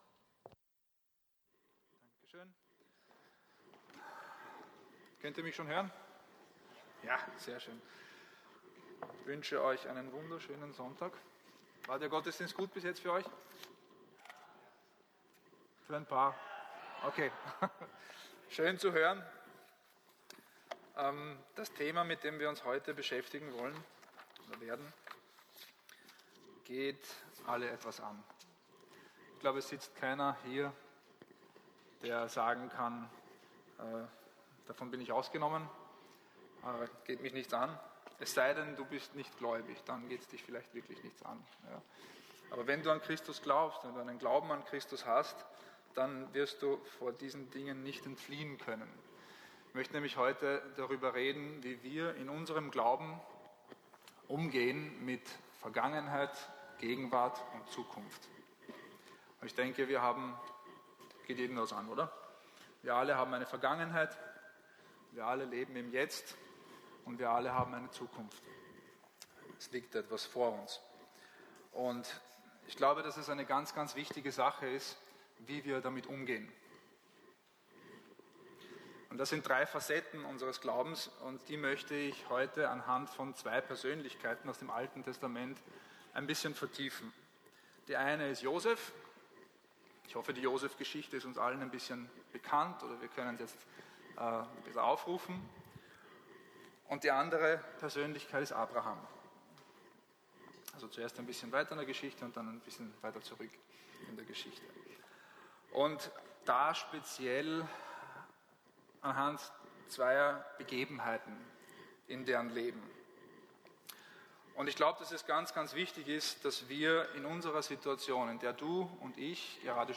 Sonntagspredigt